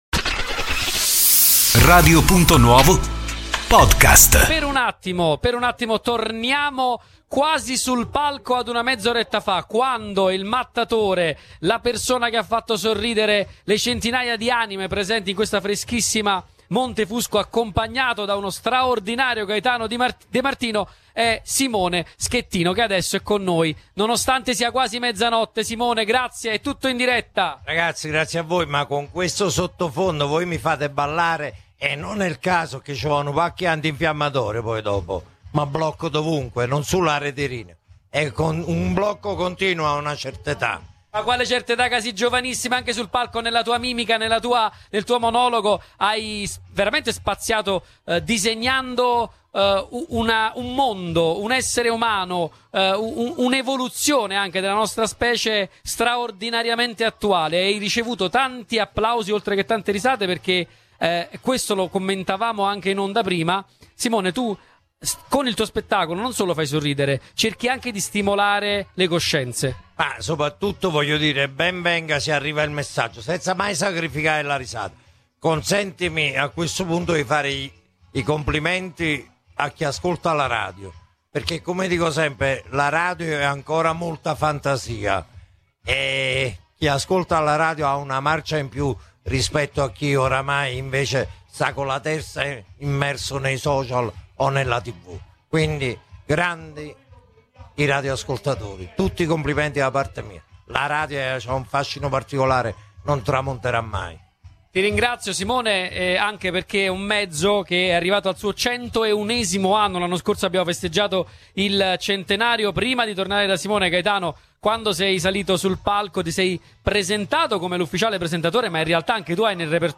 C’è un momento, a spettacolo finito, in cui l’artista smette di far ridere e si racconta.